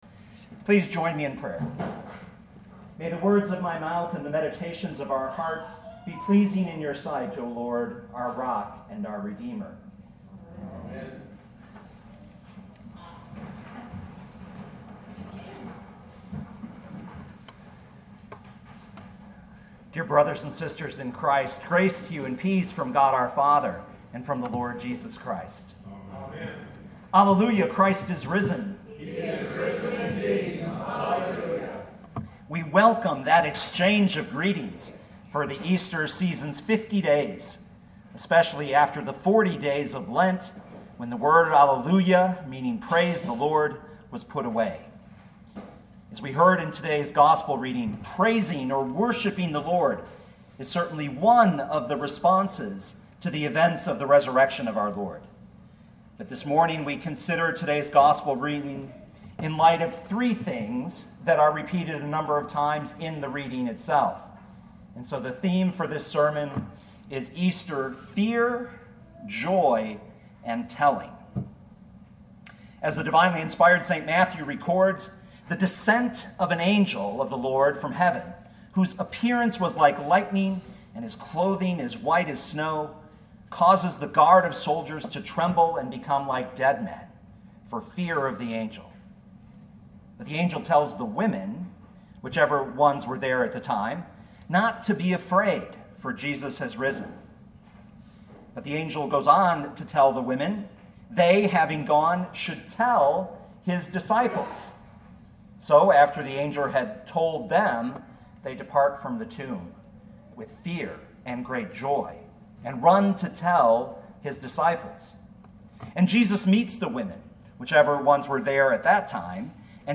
easter-fear-joy-and-telling.mp3